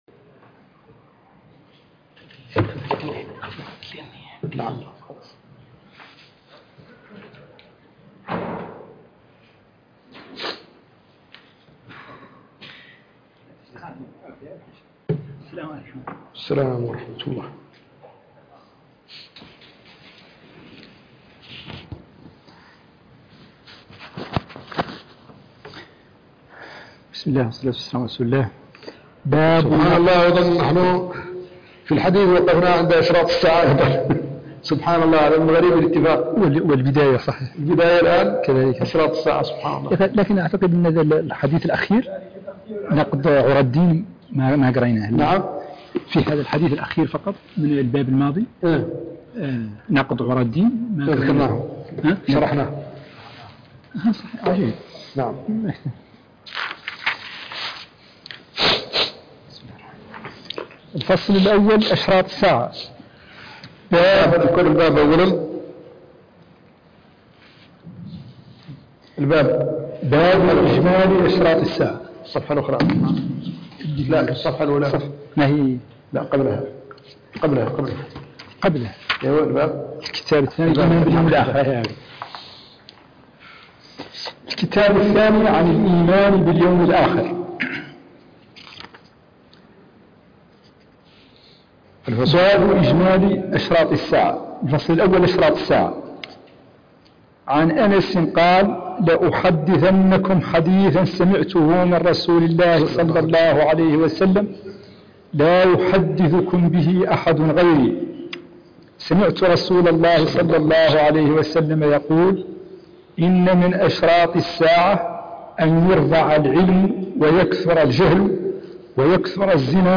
الدرس الثالث والاربعون من شرح وتعليق